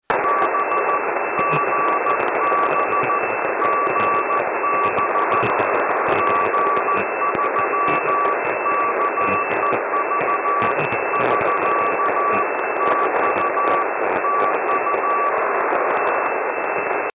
My Rig: FT857: Tx on 80m – 85W, FD-4; Rx on 502KHz – just Miniwhip ant. (CW,AGC off, 10db att.)